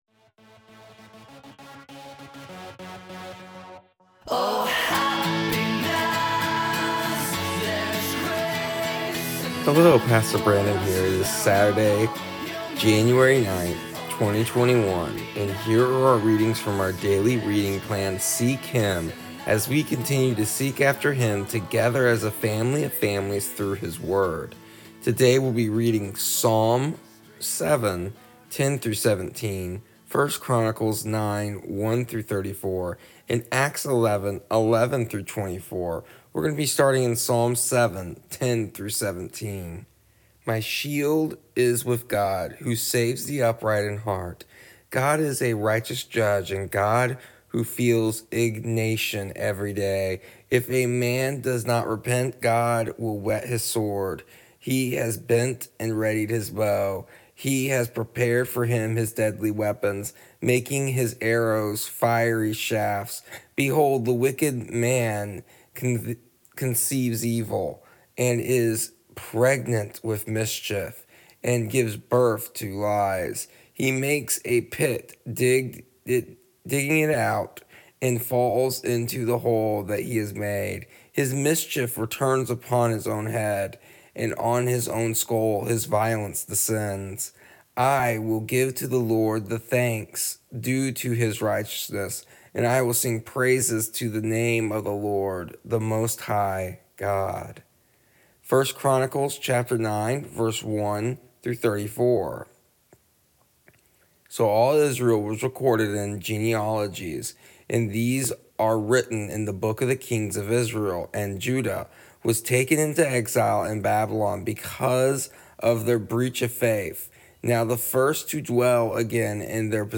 Here is the audio version of our daily readings from our daily reading plan Seek Him for January 9th, 2021.